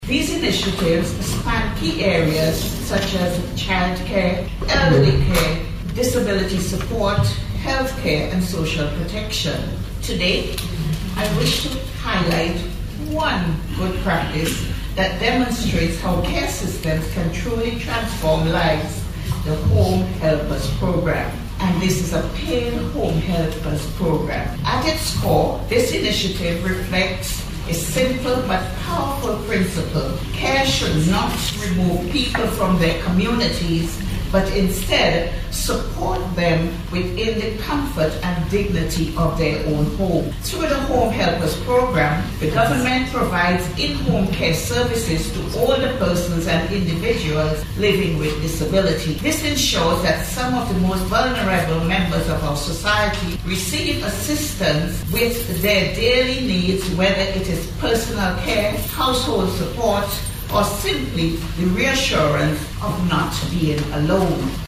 Minister responsible for the Family Hon Laverne Gibson-Velox was among delegates addressing participants at a two-day workshop held in Grenada this month.
Delivering remarks at the event, Minister Gibson-Velox said although St. Vincent and the Grenadines does not yet have a single comprehensive National Care Policy, there are several laws and policies that collectively support both Care Givers and those receiving care.